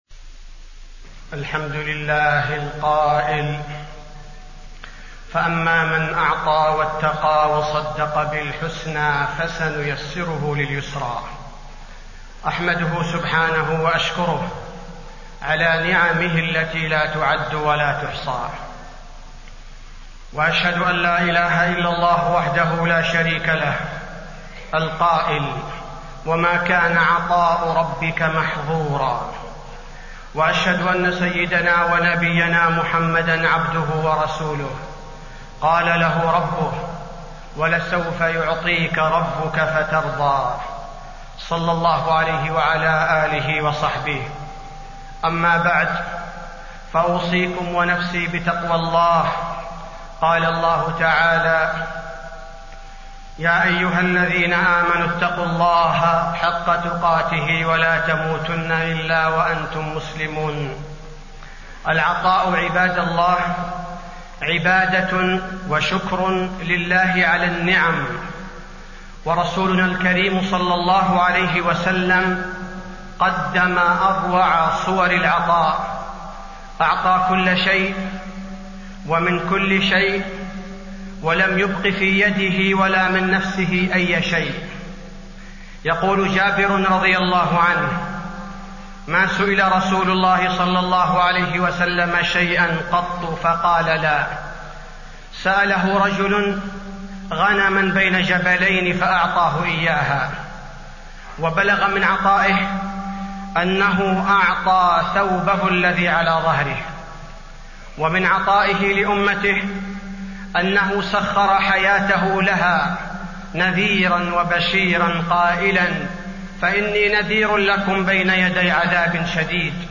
تاريخ النشر ٢٠ ربيع الأول ١٤٣٤ هـ المكان: المسجد النبوي الشيخ: فضيلة الشيخ عبدالباري الثبيتي فضيلة الشيخ عبدالباري الثبيتي فضل العطاء والسخاء